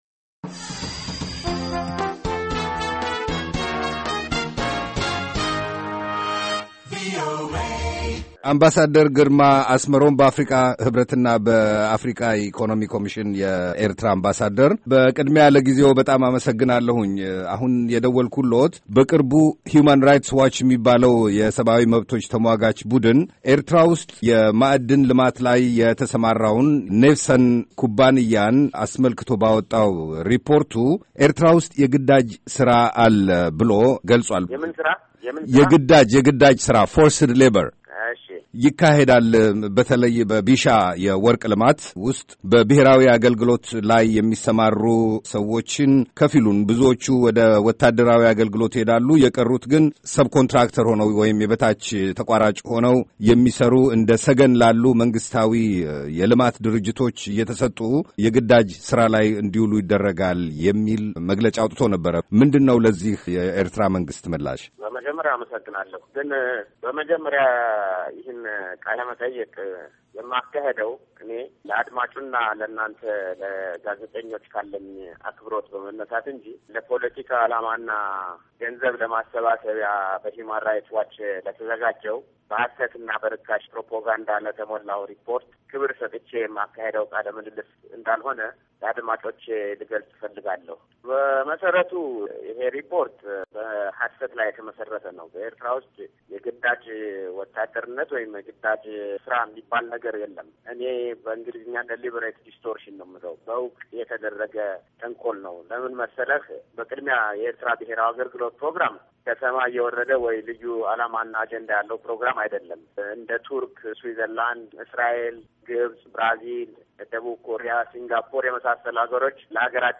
ሂዩማን ራይትስ ዋች በኤርትራ የግዳጅ ሥራ ስለመኖሩ በሠነዘረው ክሥ ላይ ከአምባሣደር ግርማ አስመሮም ጋር የተደረገ ቃለ-ምልልስ